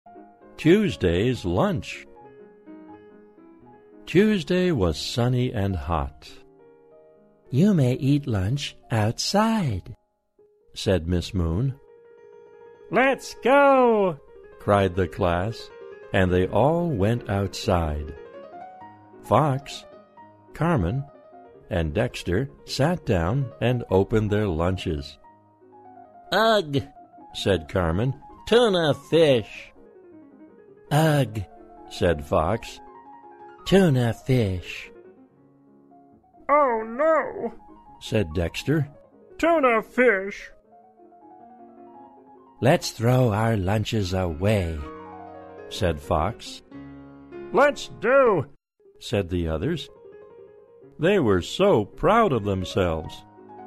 在线英语听力室小狐外传 第18期:星期二的午餐的听力文件下载,《小狐外传》是双语有声读物下面的子栏目，非常适合英语学习爱好者进行细心品读。故事内容讲述了一个小男生在学校、家庭里的各种角色转换以及生活中的趣事。